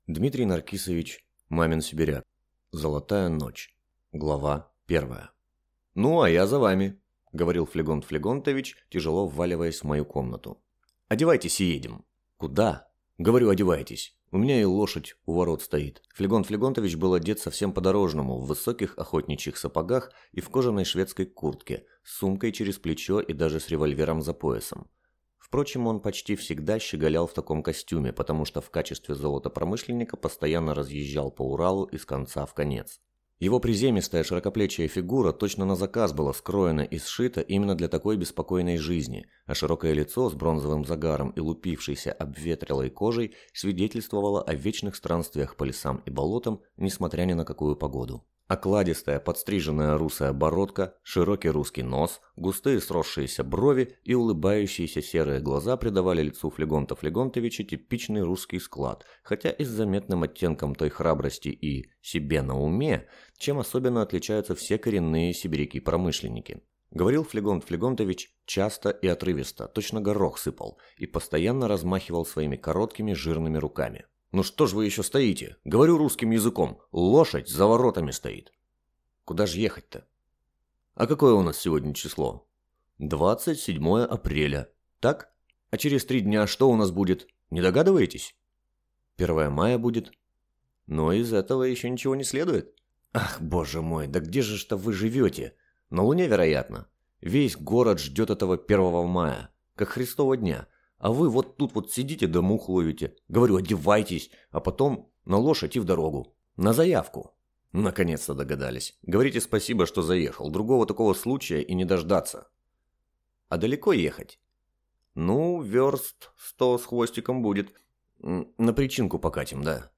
Аудиокнига Золотая ночь | Библиотека аудиокниг